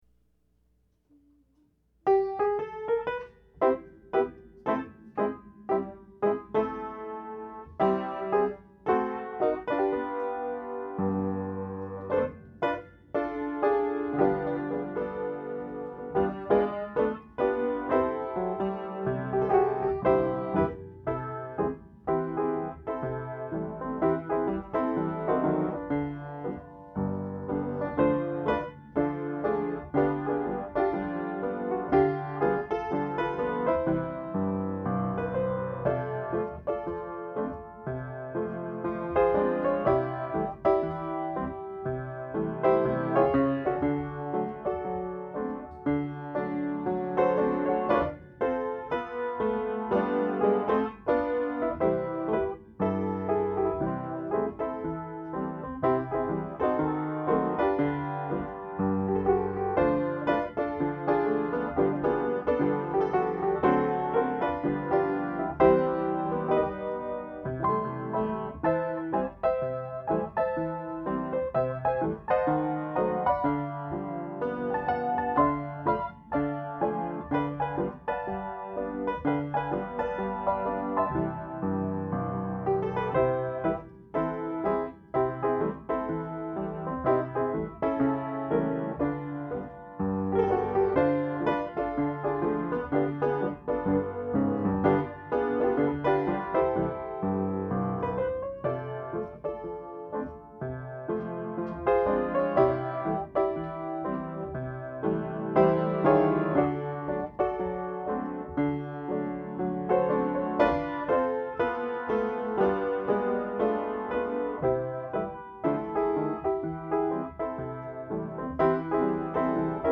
Old-Time Piano Music
old-time piano
Old time piano music from another era.